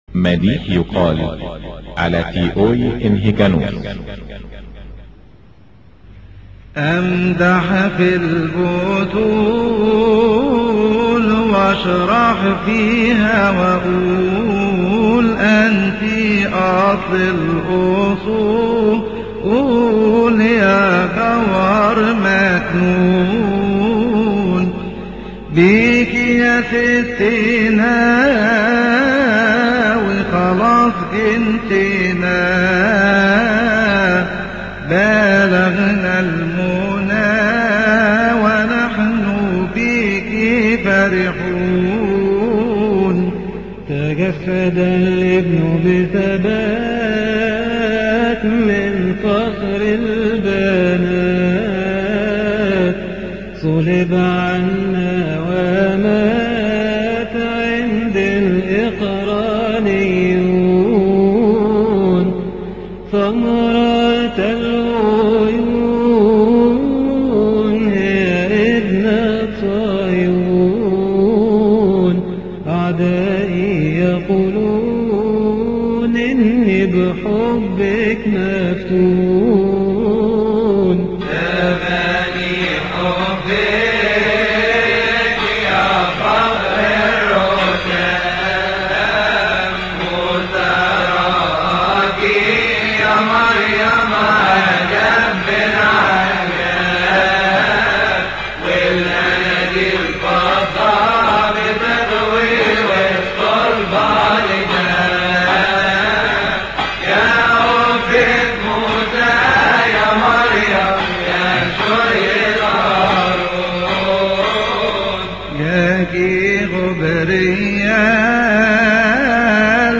مكتبة الالحان | لحن مديح أمدح في البتول - شهر كيهك -